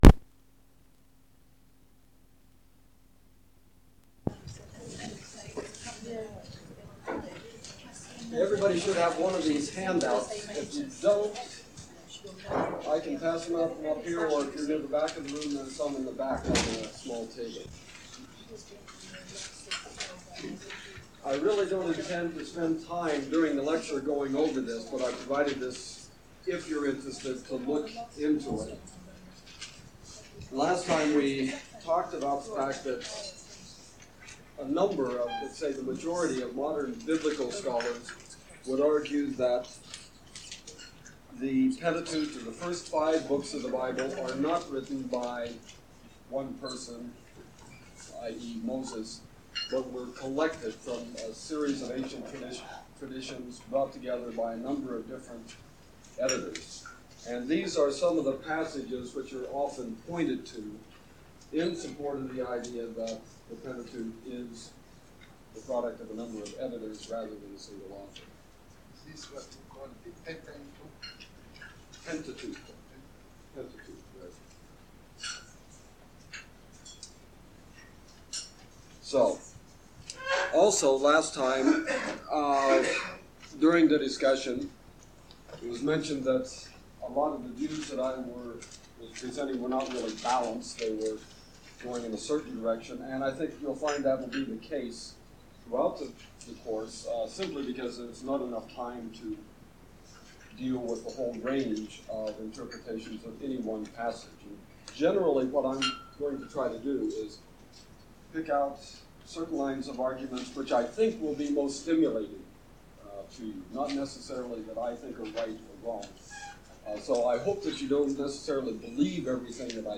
Archaeology of Jordan and Biblical History - Lecture 4